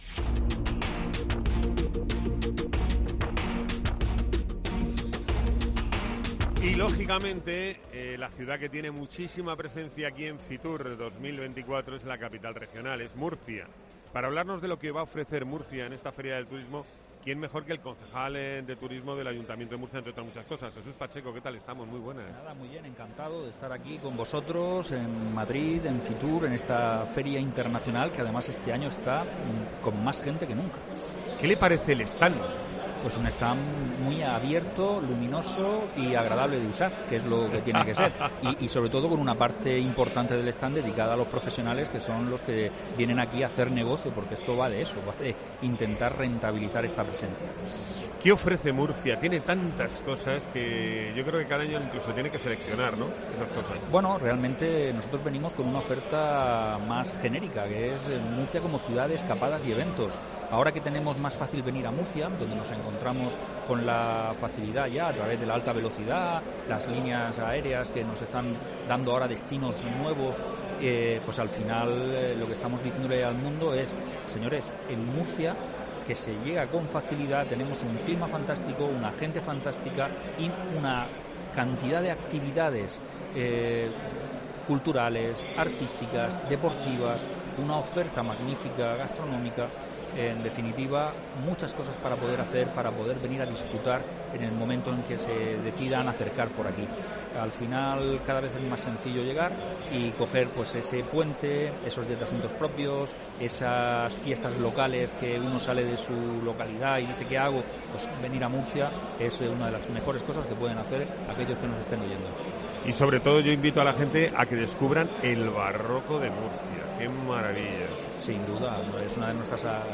FITUR 2024
Jesús Pacheco, concejal de Turismo del Ayuntamiento de Murcia, presenta en COPE Murcia las propuestas de Murcia para atraer el turismo a la capital.